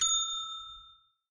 ding.mp3